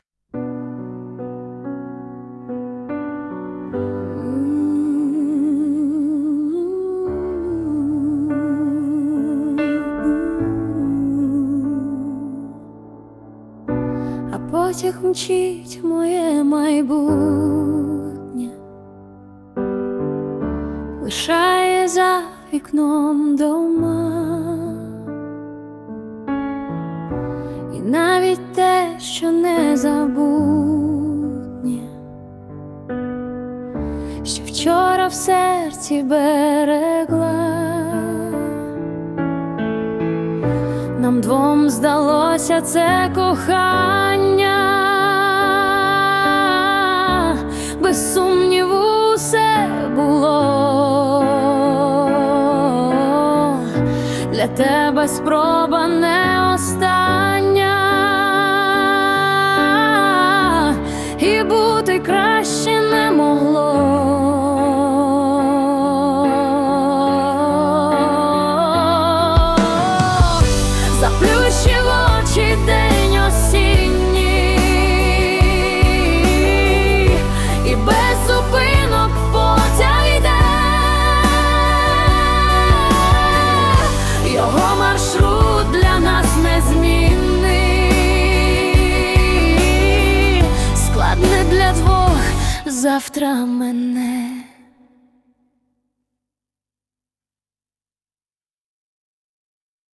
Музичний супровід створено за допомогою SUNO AI
hi Чуттєво, ніжно 12